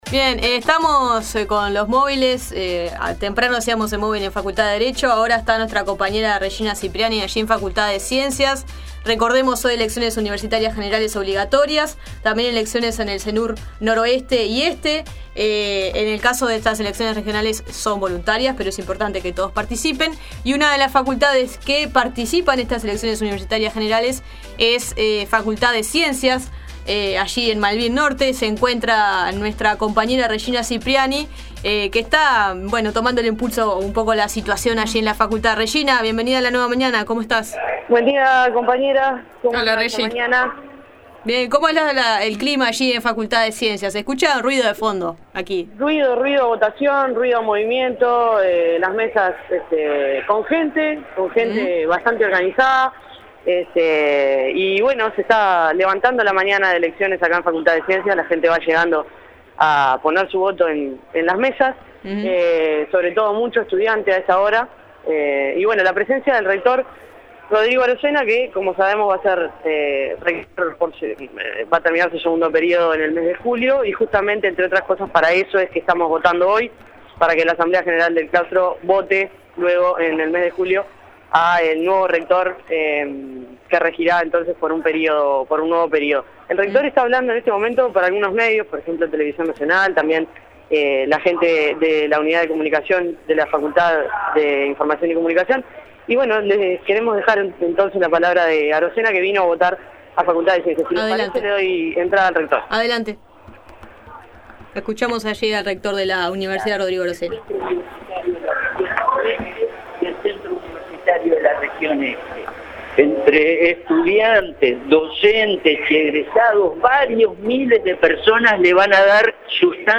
Audio: Elecciones Universitarias 2014: Movil con el Rector Rodrigo Arocena desde Facultad de Ciencias
Desde el móvil de Facultad de Ciencias, el Dr. Rodrigo Arocena conversó con la prensa minutos antes de su última votación como Rector de la Universidad, y UNI Radio dialogó con él.